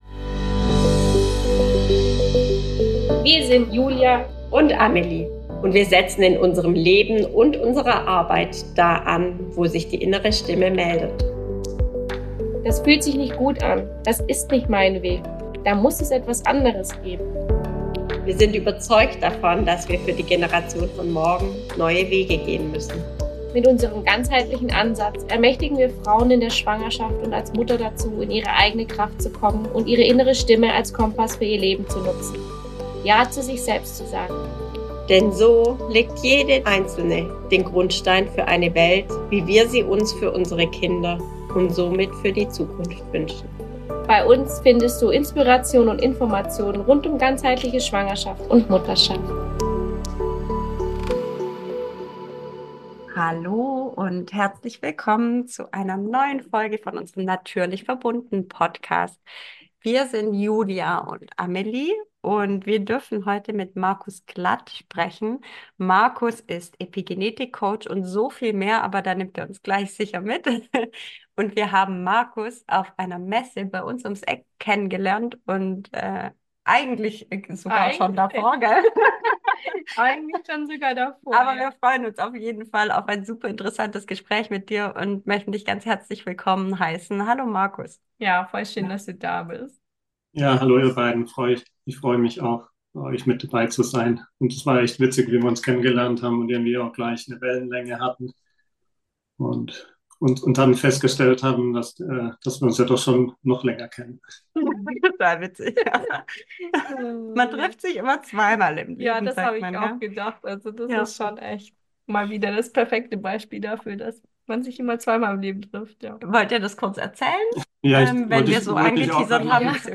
Er ist Epigenetik Coach und wir haben uns auf einer Messe kennen gelernt.